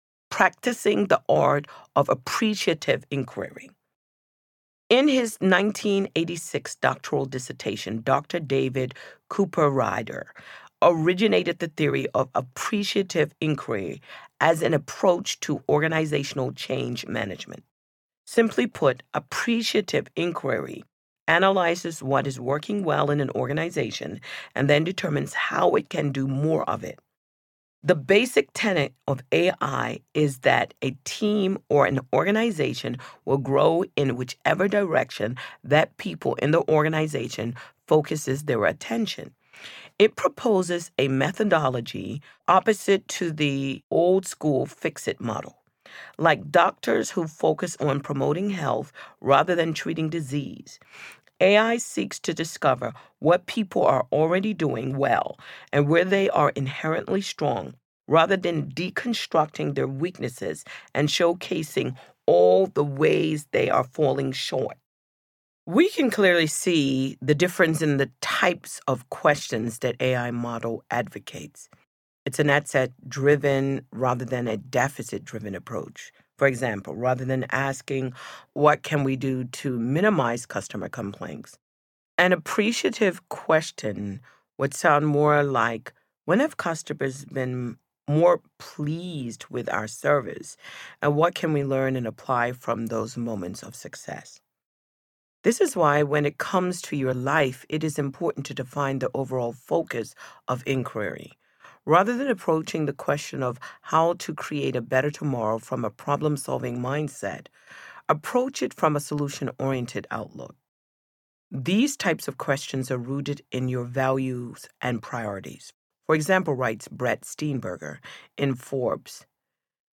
Hello, Tomorrow! Audiobook